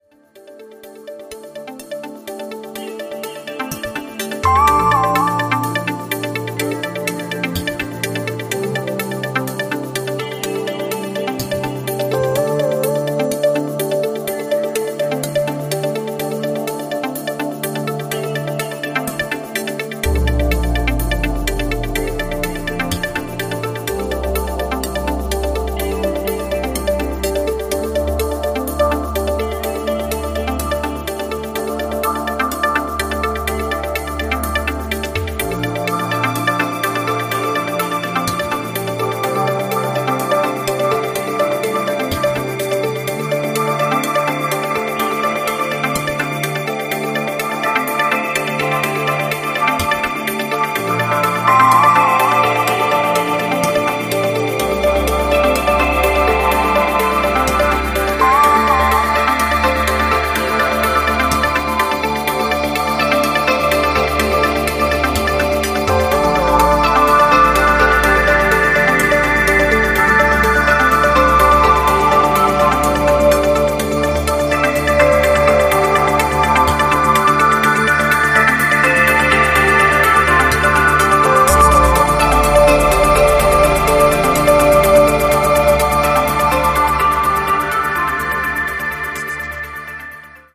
また、B面にはビートレスのバージョンとビート主体の別バージョンも収録。'